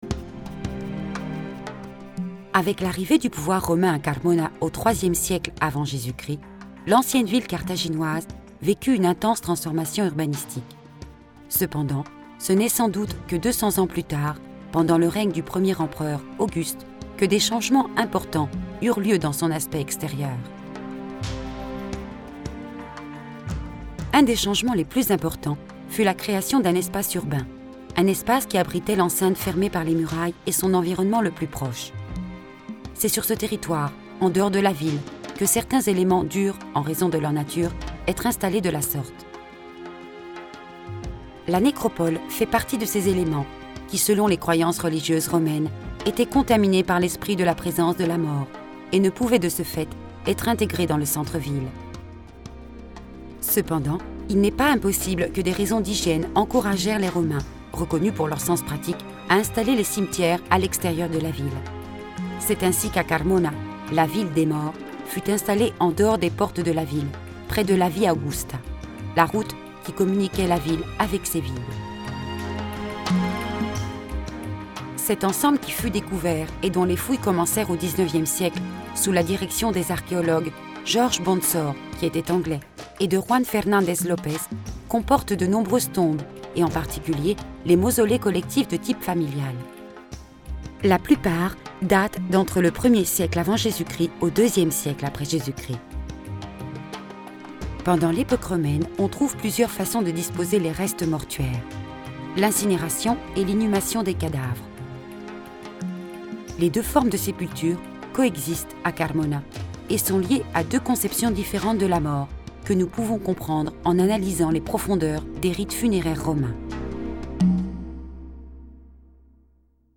Audioguides pour la visite de Carmona